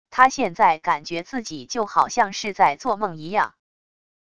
他现在感觉自己就好像是在做梦一样wav音频生成系统WAV Audio Player